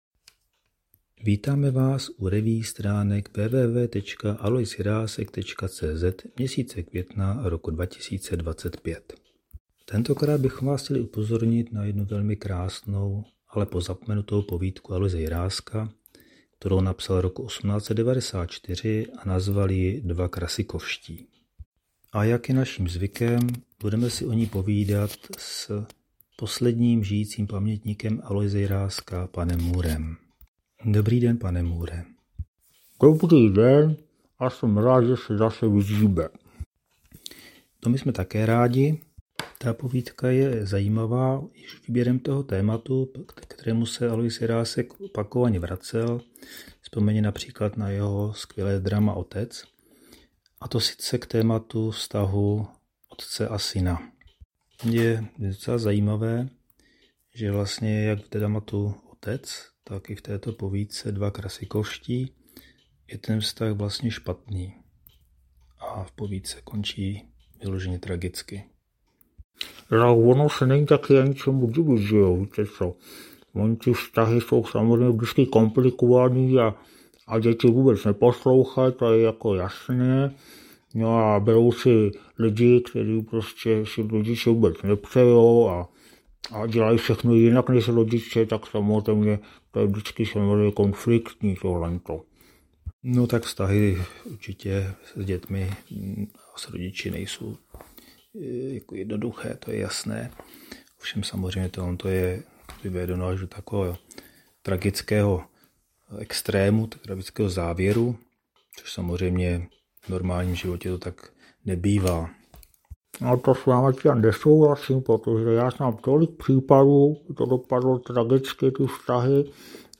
Poslechněte si obvyklou zvukovou revue, tentokrát zakončenou četbou Jiráskovy mistrné povídky “Dva Krasikovští”.
Další amatérský pokus o zvukovou revue se s obvyklou humornou nadsázkou věnuje mnohdy napjatým vztahům mezi otcem a synem, a to na pozadí mistrovské, ovšem bohužel pozapomenuté Jiráskovy povídky “Dva Krasikovští” z roku 1894.